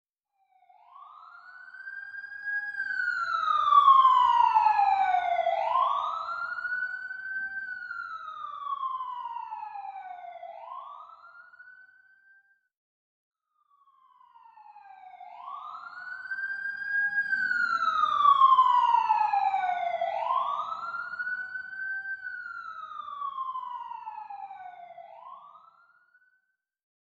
ไซเรนตำรวจจากระยะไกลและใกล้
หมวดหมู่: เสียงการจราจร
คำอธิบาย: เสียงแตรรถตำรวจจากที่ไกลและใกล้คือเสียงรถตำรวจที่ส่งเสียงดังในอวกาศ ทำนายว่ารถตำรวจกำลังเข้ามาใกล้และผ่านไป เสียงไซเรนตำรวจหอนเป็นเสียงที่ชัดเจนซึ่งปรากฏมากในฉากตำรวจจับโจร
tieng-coi-xe-canh-sat-tu-xa-den-gan-th-www_tiengdong_com.mp3